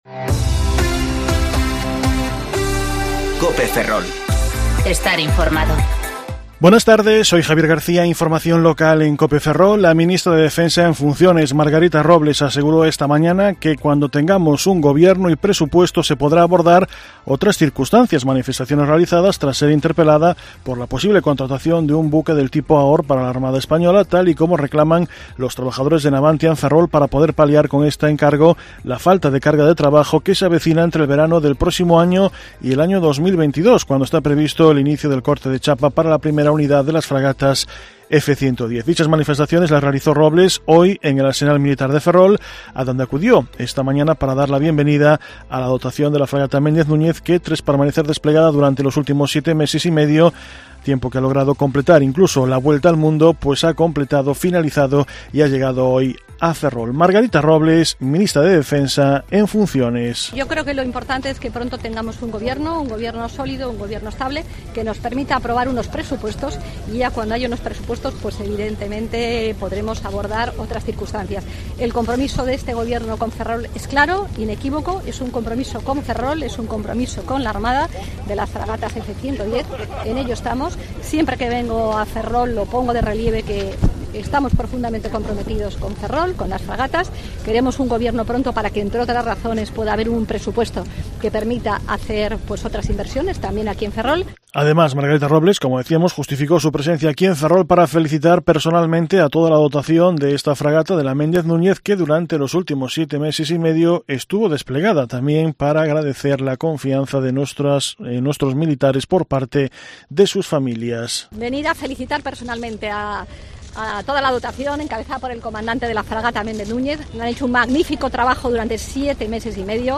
Informativo Mediodía Cope Ferrol 28/11/2019 (De 14.20 a 14.30 horas)